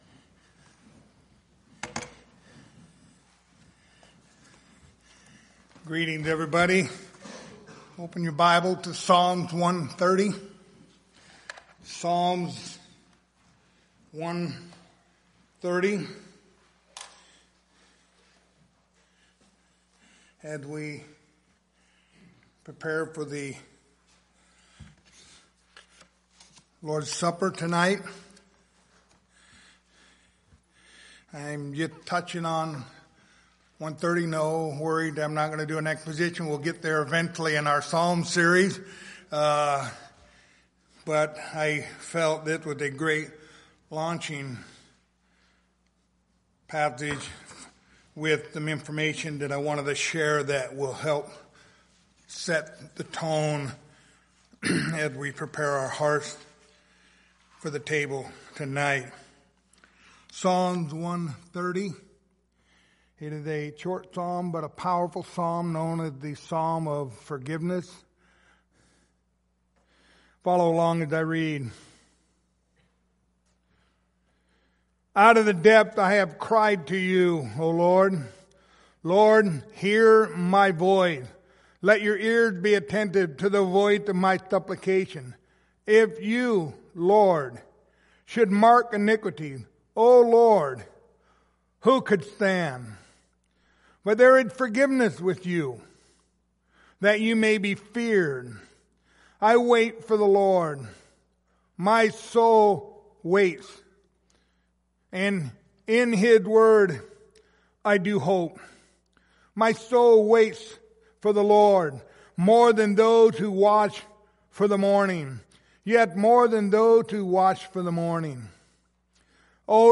Passage: Psalm 130 Service Type: Lord's Supper Topics